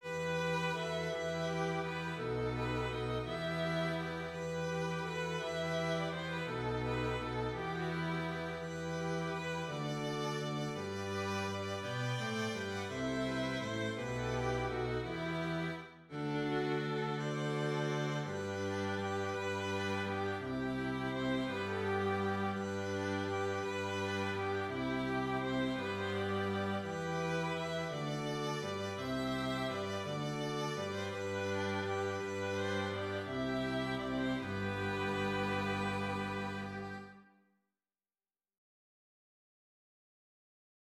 Includes score and parts for string ensemble.